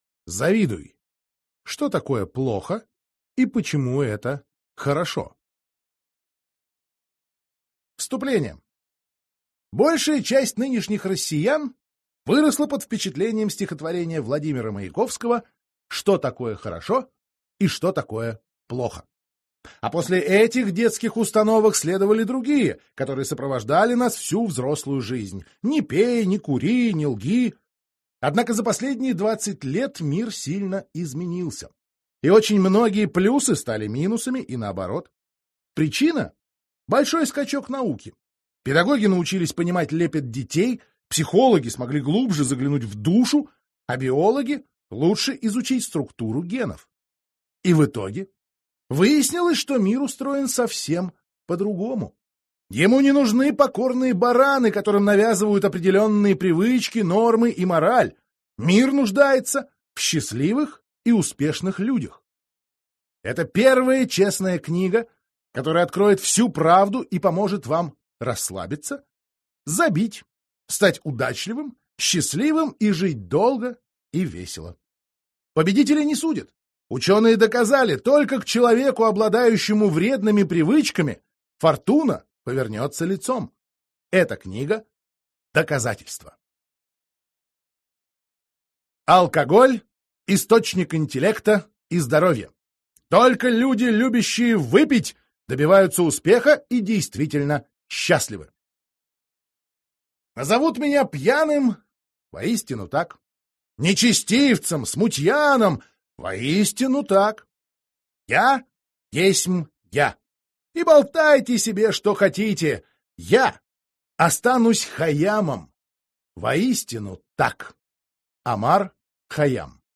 Аудиокнига Ври! Ленись! Завидуй! Что такое плохо и почему это хорошо. Антикнига для умных | Библиотека аудиокниг